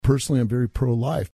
on KNUS radio on May 7